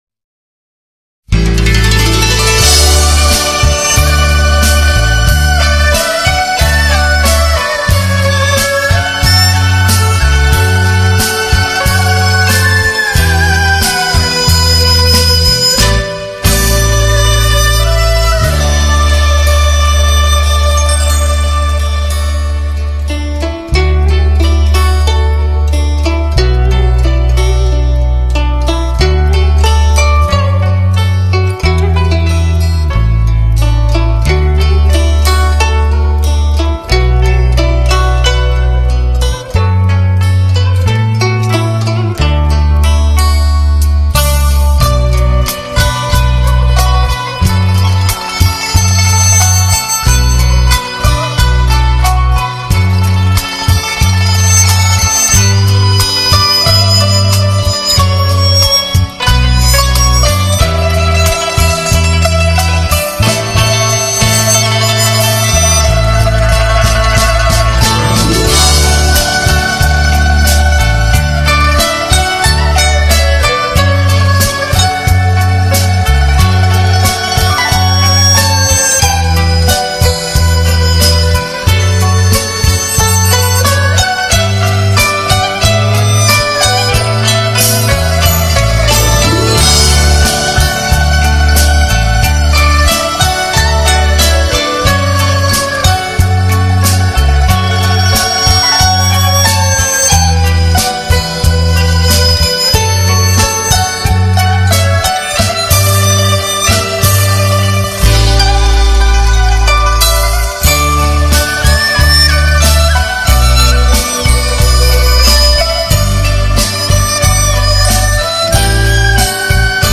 中国民乐的音色和风格独具民族特色，很多民族乐器演奏技法多样，音色优美动听，余音袅袅，独具韵味。